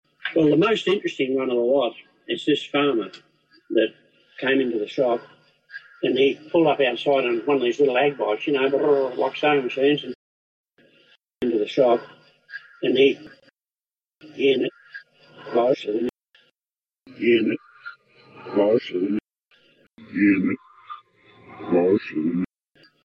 AUDIO ENHANCED